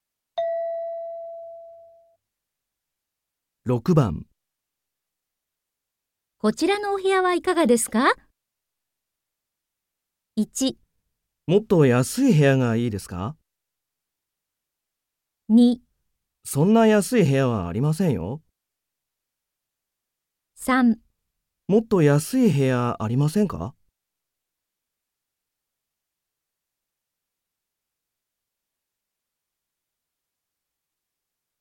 問題4 ［聴解］